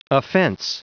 Prononciation du mot offence en anglais (fichier audio)
Prononciation du mot : offence